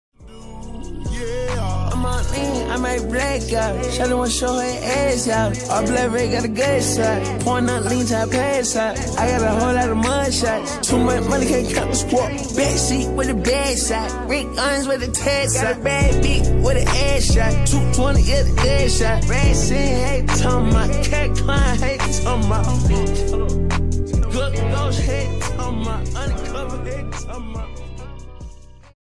[slowed]